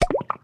Potion Drink (3).wav